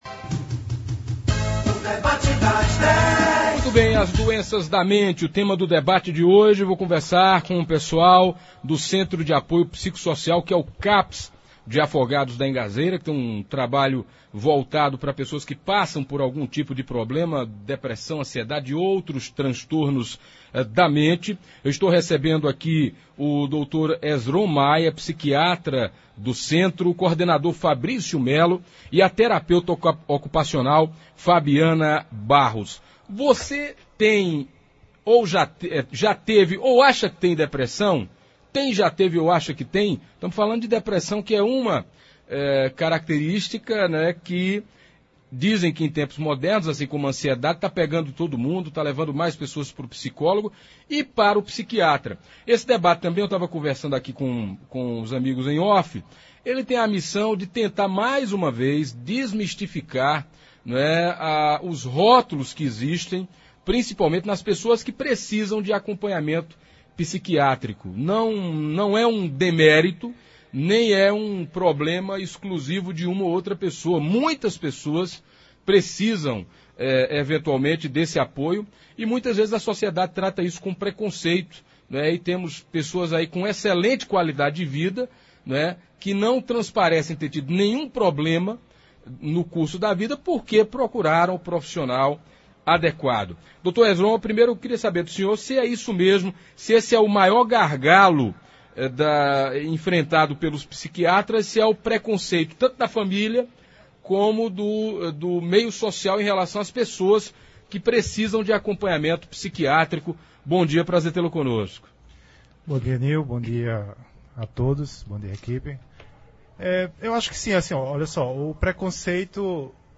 Também falaram sobre o funcionamento do CAPS de Afogados e como são realizados os trabalhos. Ouça abaixo na íntegra como foi o debate de hoje: